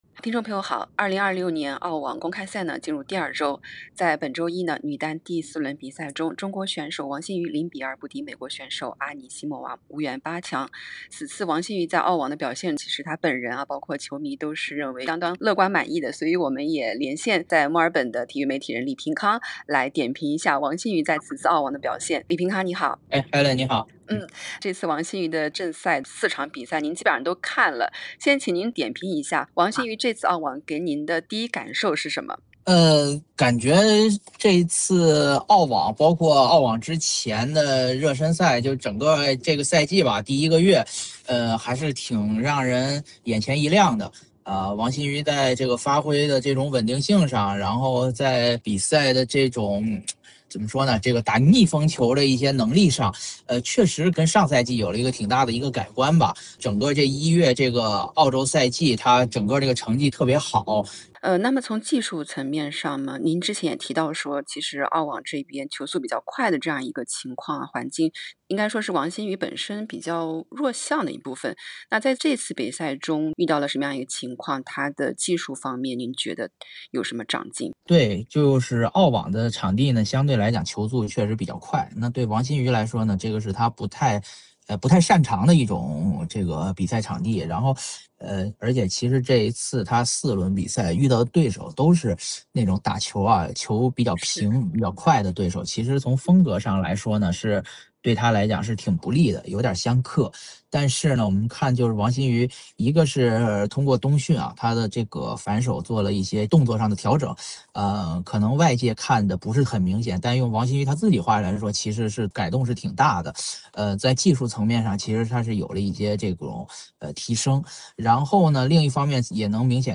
点击播放键收听完整采访 【澳网2026】止步16强 王欣瑜新赛季“涨球”从何体现？